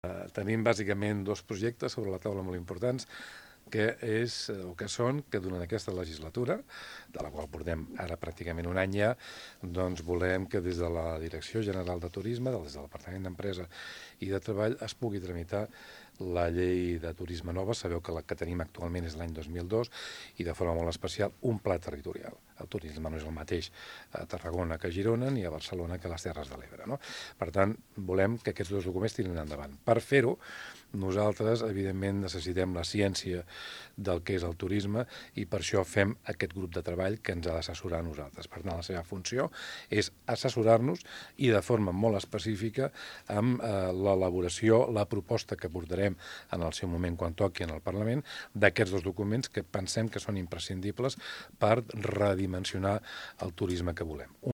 “El turisme no és el mateix a Tarragona que a Girona i a Barcelona que a les Terres de l’Ebre”, ha dit el conseller aquest dilluns en una atenció a mitjans abans de la constitució del grup d’experts.